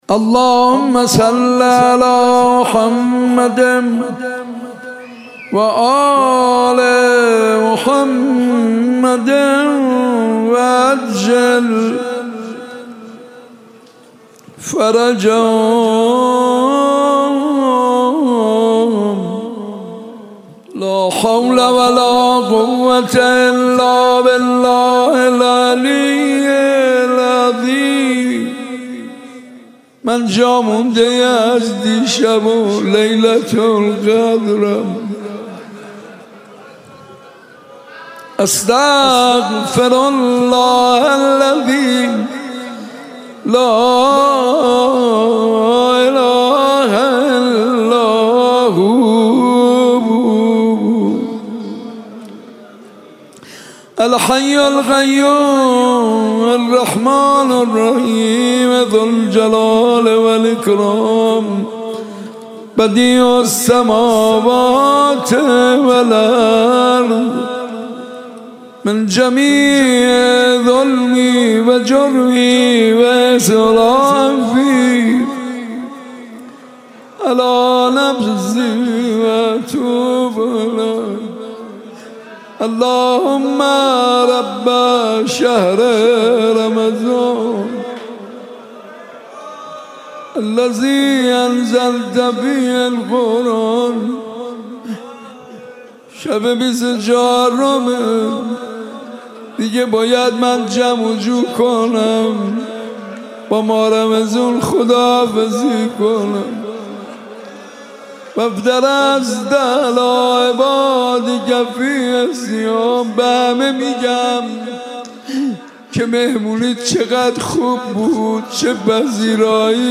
در شب بیست و چهارم ماه رمضان
مناجات زیبا و دلنشین